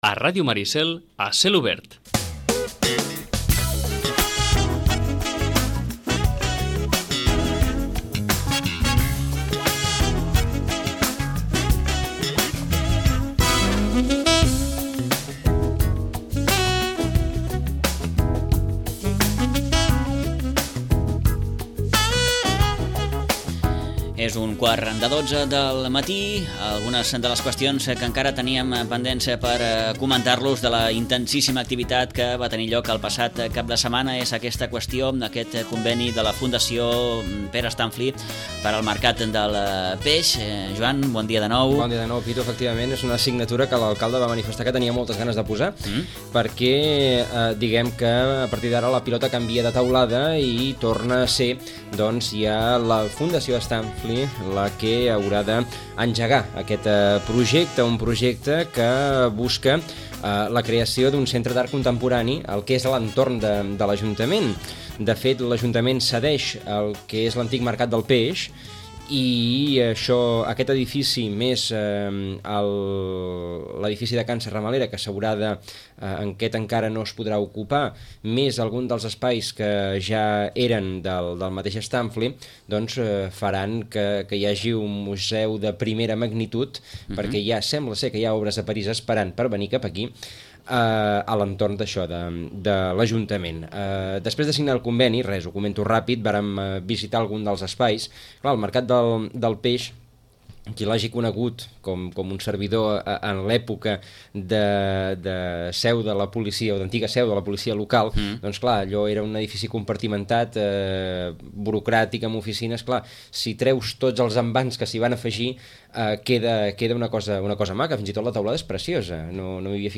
L’alcalde de Sitges, Jordi Baijet, i el pintor Pere Stämpfli, han signat el conveni de cessió del Mercat del Peix a la Fundació Stämpfli, amb la intenció d’afegir-lo al que en el futur convertirà en carrer d’en Bosch en una seu d’art contemporani de primer ordre. Ho expliquen tots dos en roda de premsa.